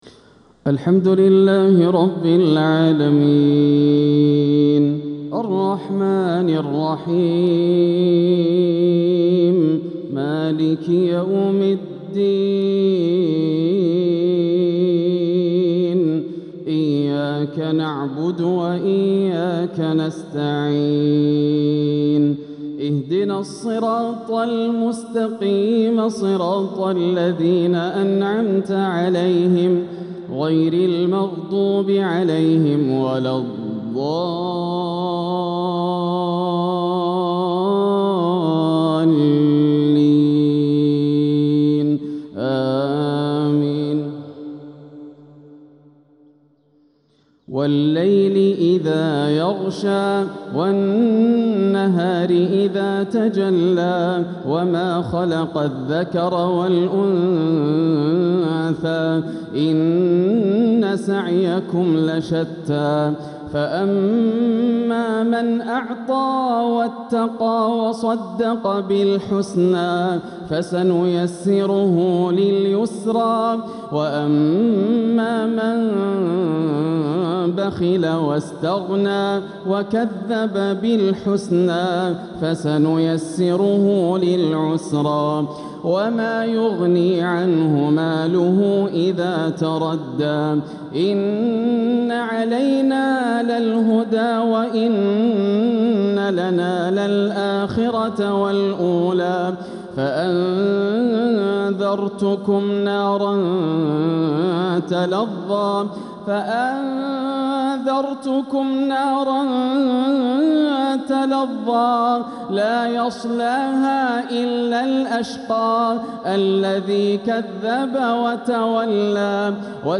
تلاوة لسورتي الليل والعصر | مغرب الثلاثاء 4-6-1447هـ > عام 1447 > الفروض - تلاوات ياسر الدوسري